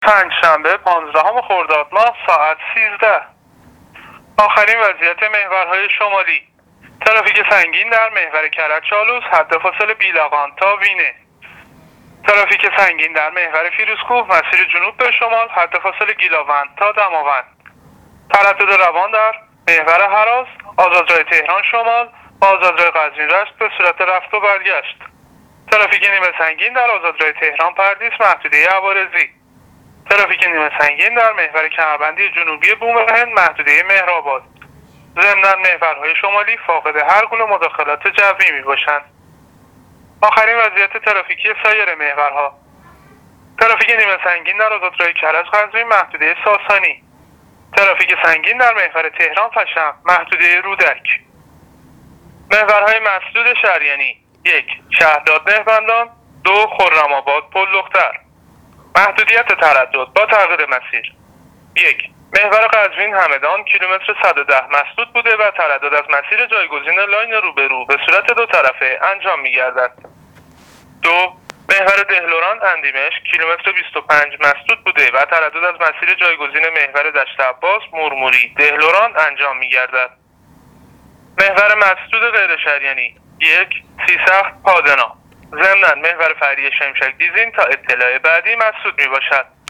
گزارش رادیو اینترنتی از وضعیت ترافیکی جاده‌ها تا ساعت ۱۳پانزدهم خردادماه